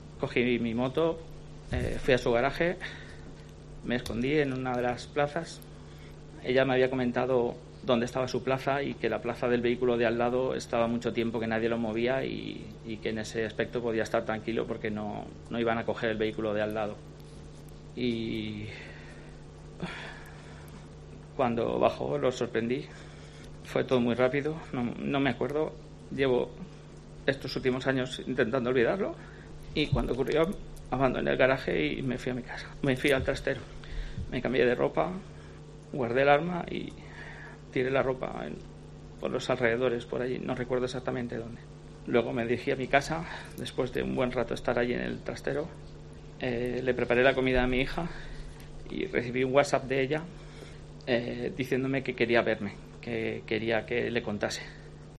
Con muchos silencios durante su declaración y suspiros